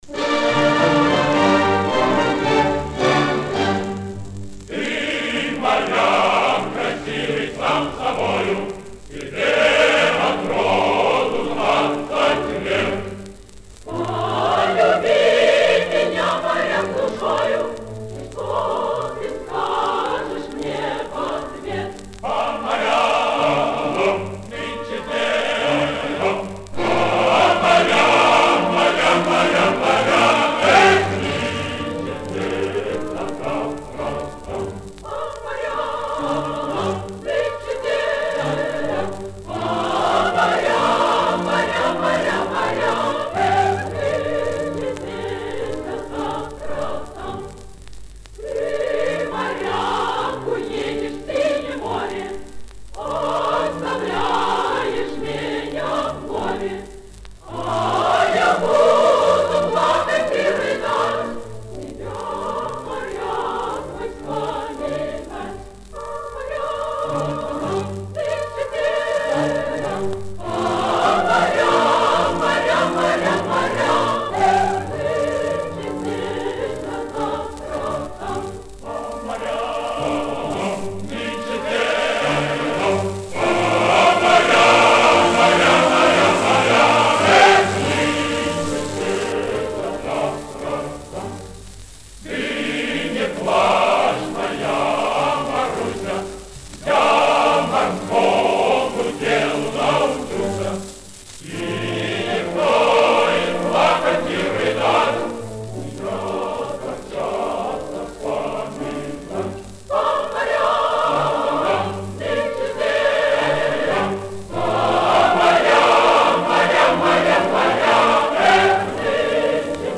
Комсомольская революционная песня.